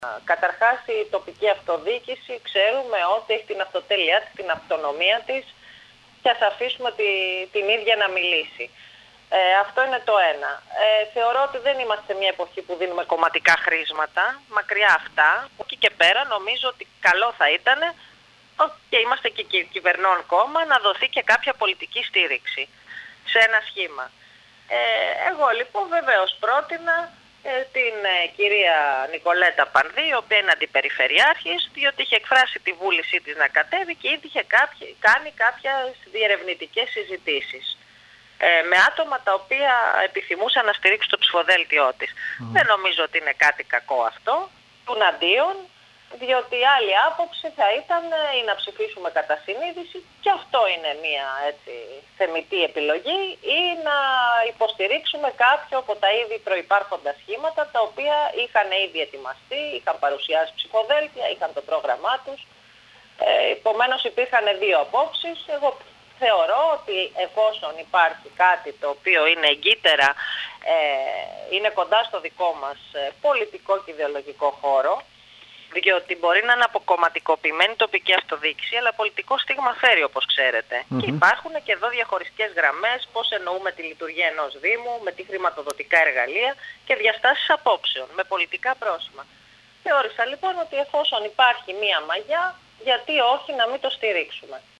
Η κυρία Βάκη μιλώντας στο σταθμό μας, τόνισε ότι η αυτοδιοίκηση αν και θα πρέπει να μένει μακριά από τις πολιτικές αντιπαραθέσεις, διαθέτει πολιτικό πρόσημο αφού ο τρόπος με τον οποίο οι αιρετοί διαχειρίζονται ακόμη και τα προβλήματα της καθημερινότητας αφορά αποφάσεις που σε διαφορετικό βαθμό έχουν πολιτικά χαρακτηριστικά. Στο πλαίσιο αυτό, πρότεινε τη στήριξη της κυρίας Πανδή, η οποία έχει δώσει δείγματα δημοκρατικής γραφής,  ευγένειας και προοδευτικού προσανατολισμού κατά τη διάρκεια της θητείας της.